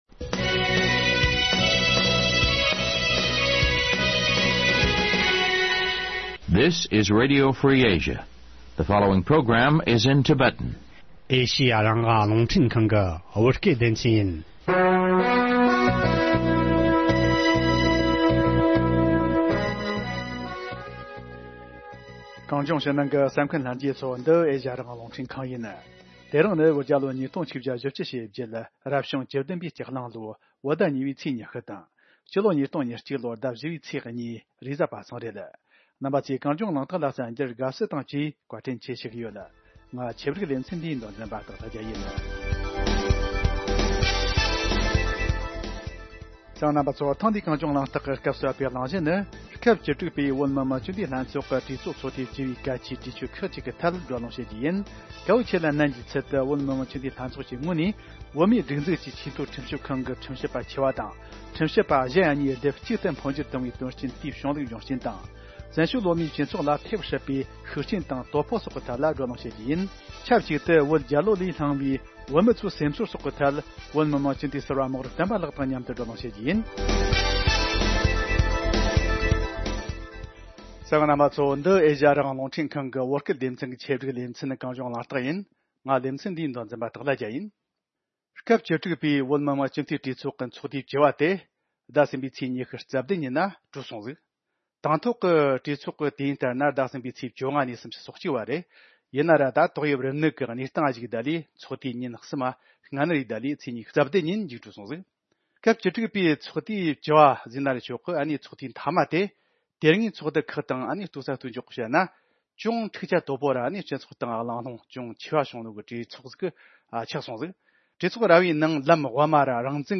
སྐབས་བཅུ་དྲུག་པའི་བོད་མི་དམངས་སྤྱི་འཐུས་ལྷན་ཚོགས་ཀྱི་གྲོས་ཚོགས་ཚོགས་དུས་བཅུ་པའི་གལ་ཆེའི་གྲོས་ཆོད་ཁག་གི་ཐད་བགྲོ་གླེང་ཞུས་པ།